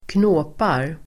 Uttal: [²kn'å:par]